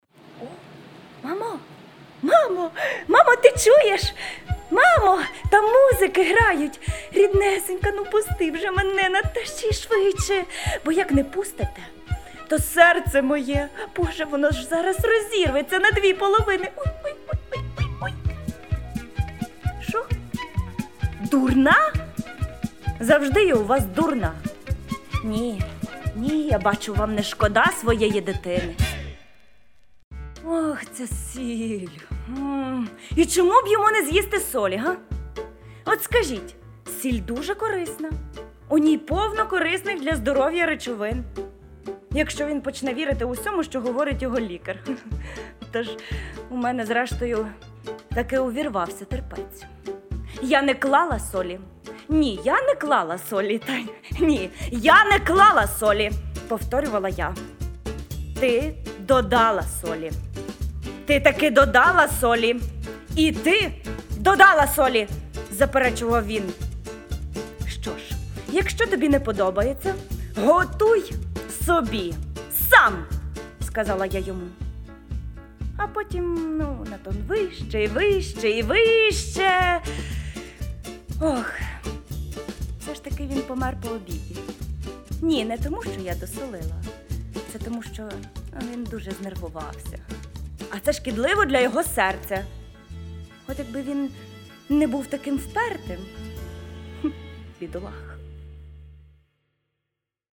Образ речей. Монолог
Уривки з монологів